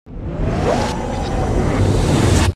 DoorClose.wav